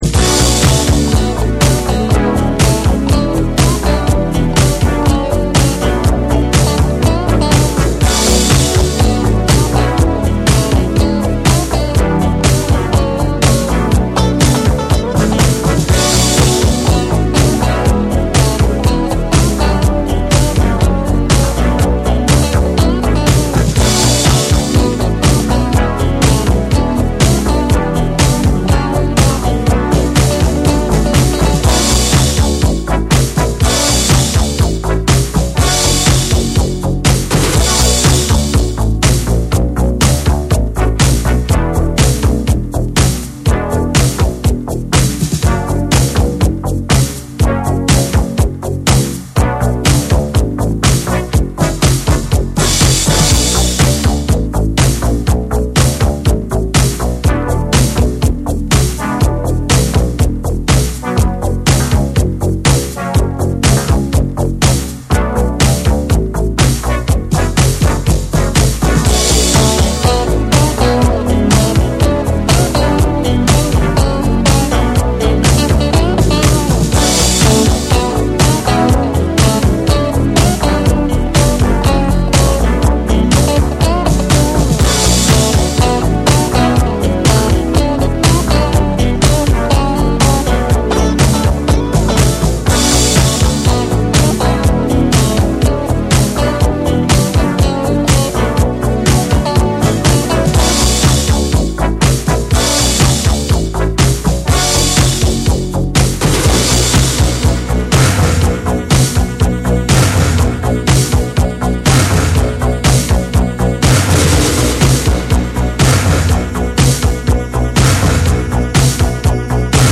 アーバンな雰囲気とグルーヴ感を持つ
DANCE CLASSICS / DISCO / RE-EDIT / MASH UP